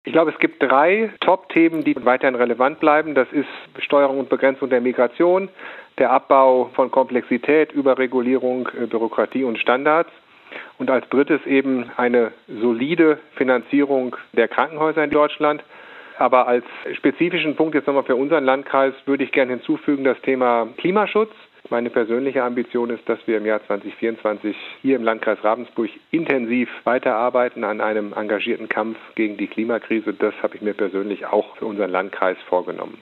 Harald Sievers (CDU), Landrat Kreis Ravensburg